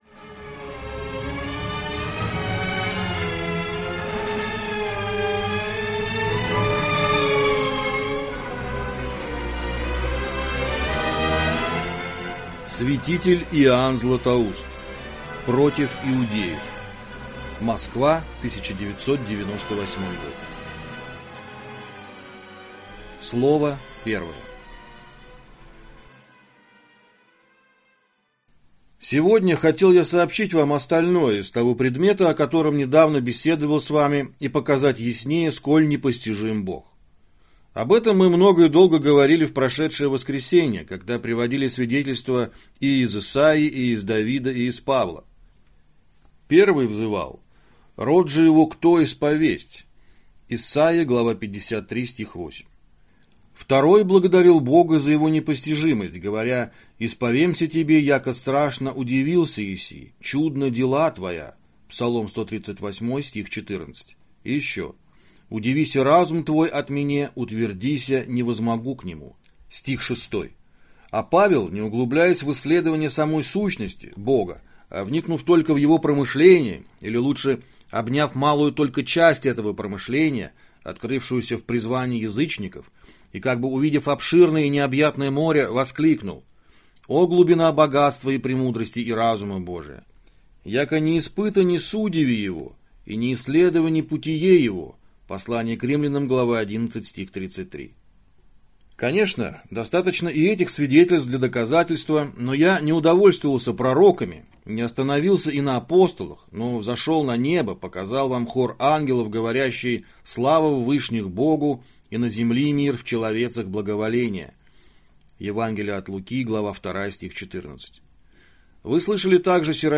Аудиокнига Святитель Иоанн Златоуст. Избранные слова | Библиотека аудиокниг